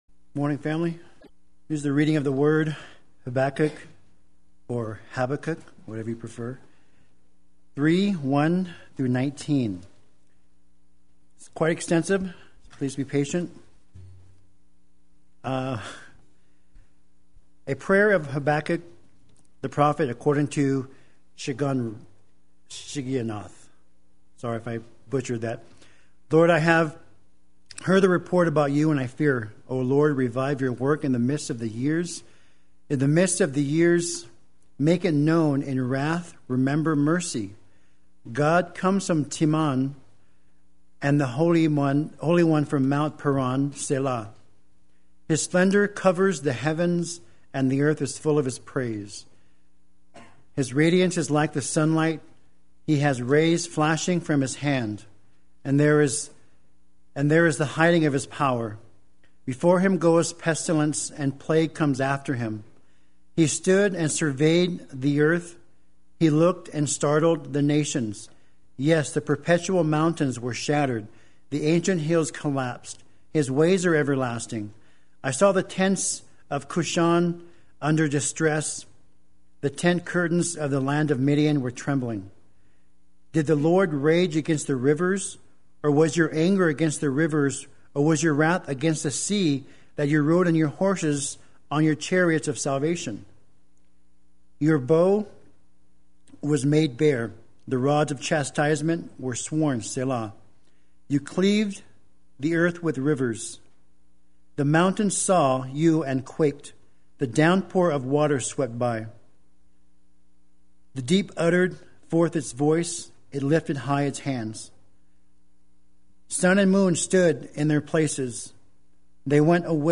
Play Sermon Get HCF Teaching Automatically.
Remembering and Rejoicing in God’s Mercy Sunday Worship